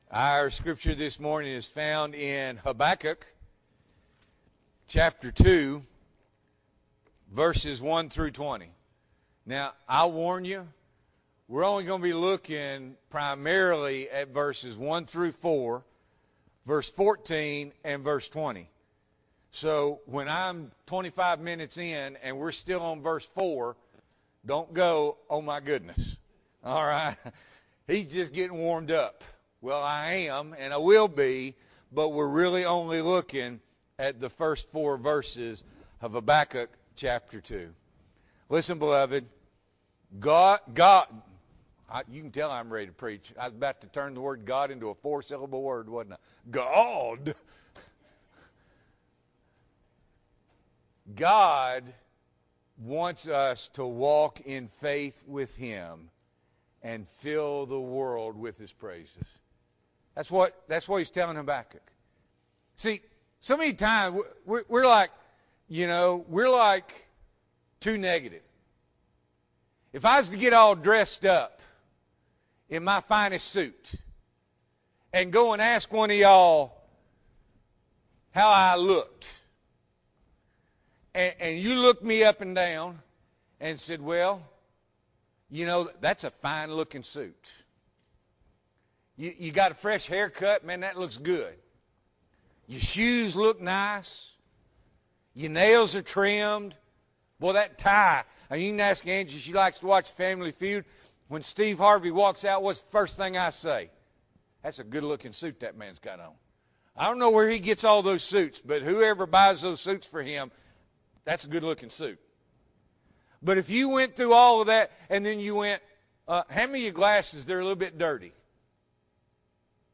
July 25, 2021 – Morning Worship